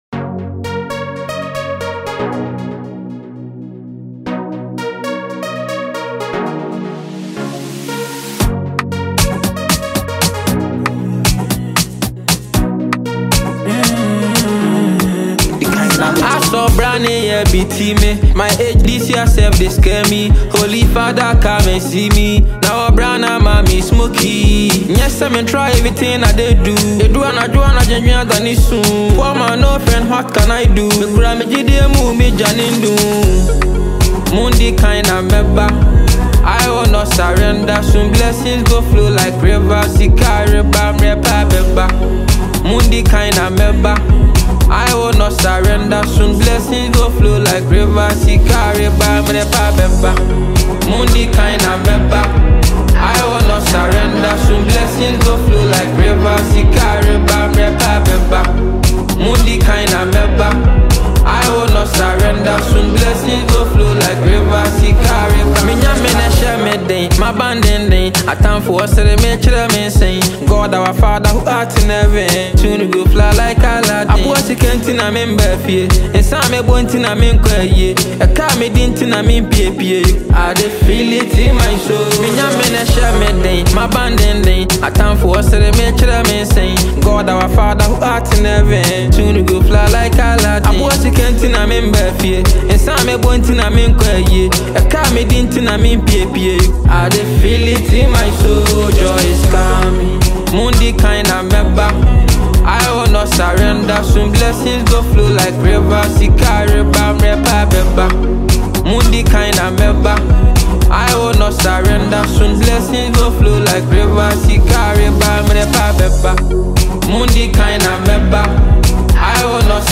” a raw and self-assured track that speaks to ambition